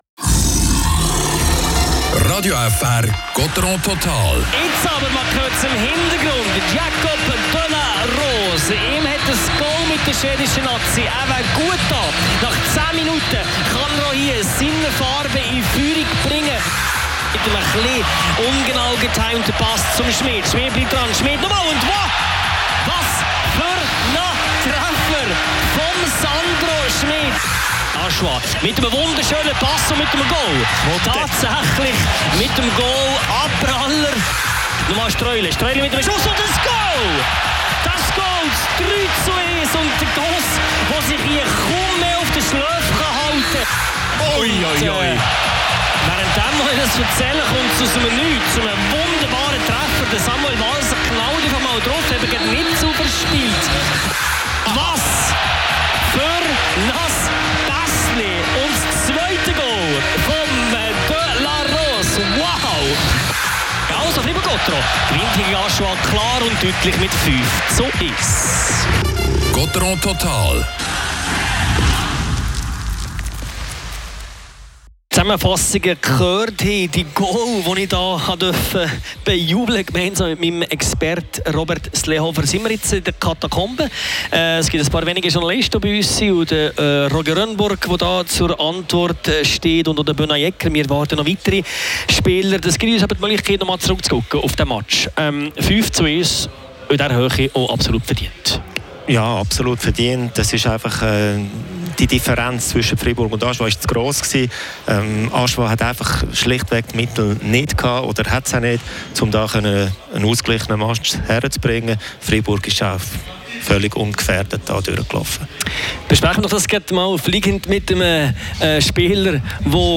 Das Spiel mit einem harmlosen Gegner, dem HC Ajoie. Gottéron gewinnt zu Hause in der BCF Arena mit 5:1. Spielanalyse
Interview